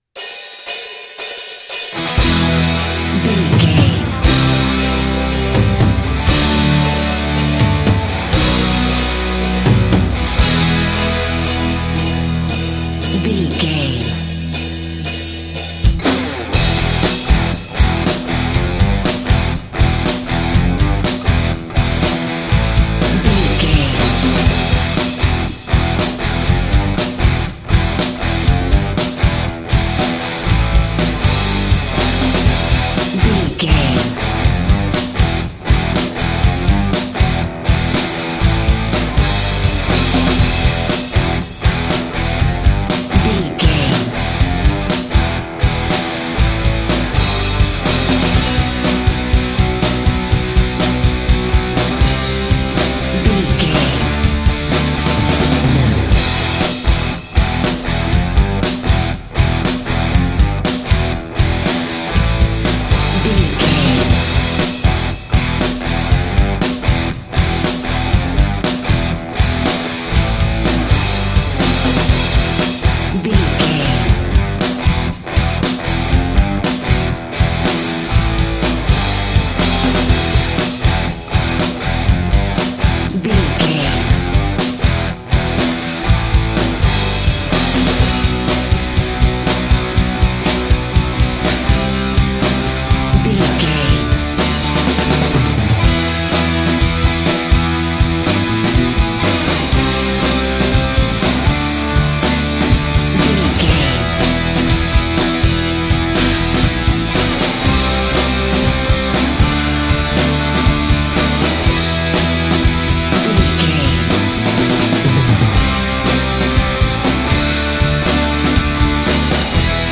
Aeolian/Minor
drums
electric guitar
pop rock
hard rock
bass
aggressive
energetic
intense
nu metal
alternative metal